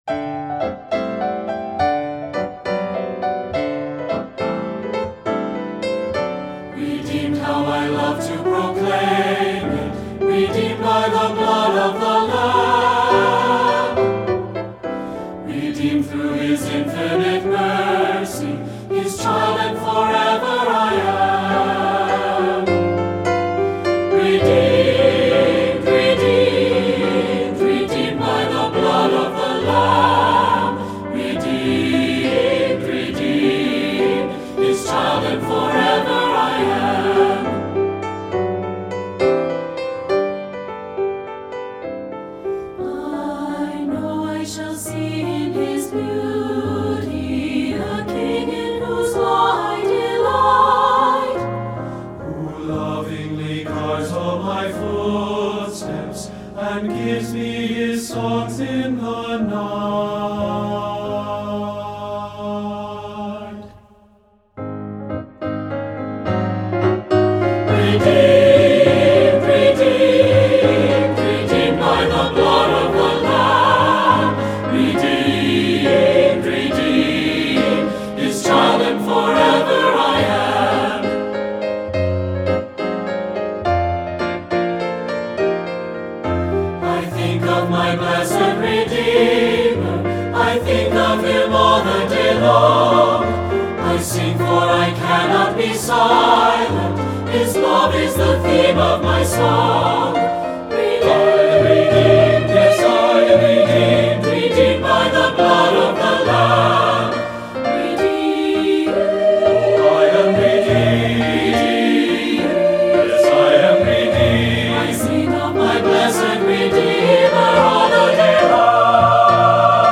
Voicing: SAT(B) and Piano